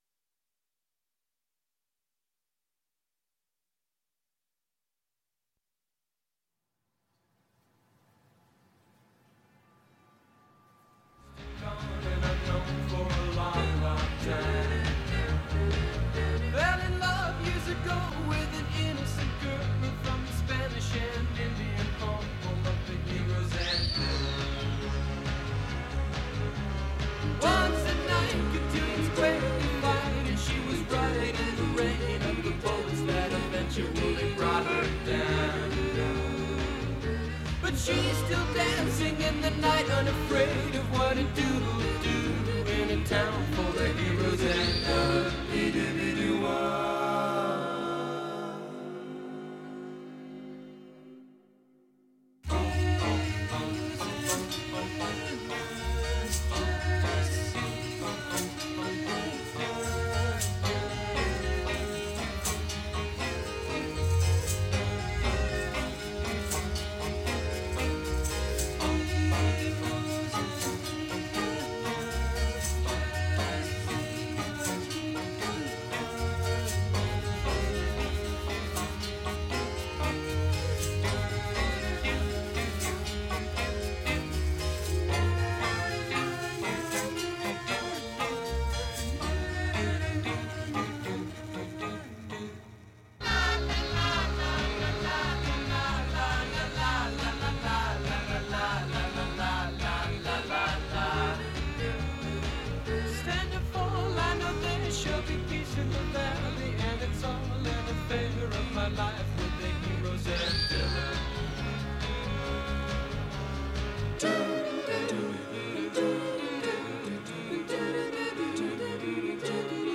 #203 56:40 Play Pause 20d ago 56:40 Play Pause Play later Play later Lists Like Liked 56:40 What gives someone the right to script your prayers? In this powerful conversation